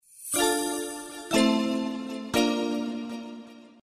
Звуки для подписки
Спокойная мелодия подписки на стрим